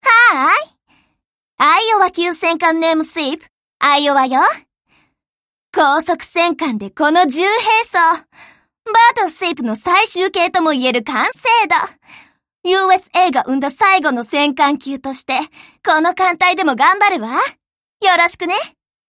• She speaks Bonin English.